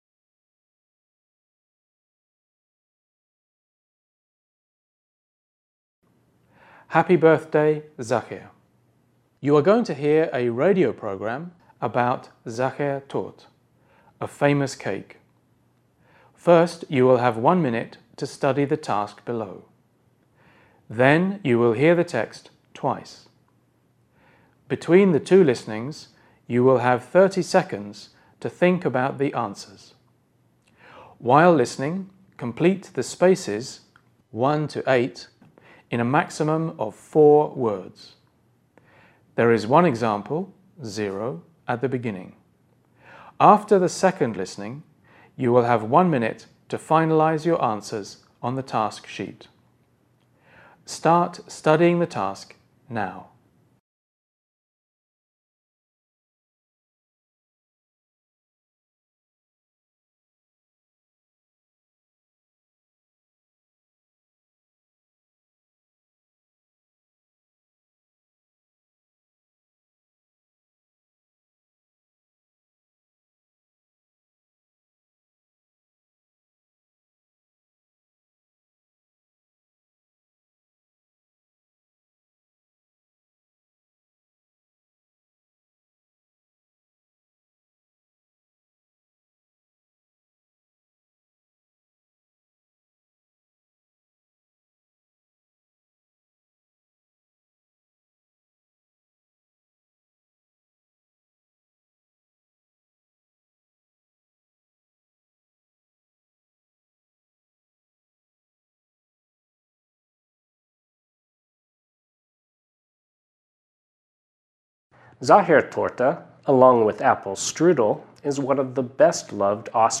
You are going to hear a radio programme about Sacher Torte, a famous cake.